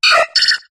togepi_ambient.ogg